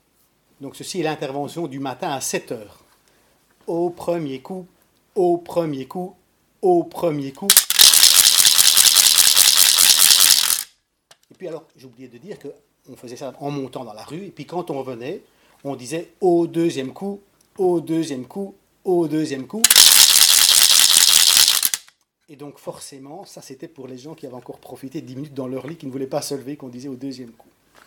Genre : chant
Type : chanson calendaire
Instrument(s) : crécelles
Lieu d'enregistrement : Institut Supérieur Royal de Musique et de Pédagogie (Namur)
Il s'agit ici de la première intervention du Vendredi saint chantée par les garçons à 7h.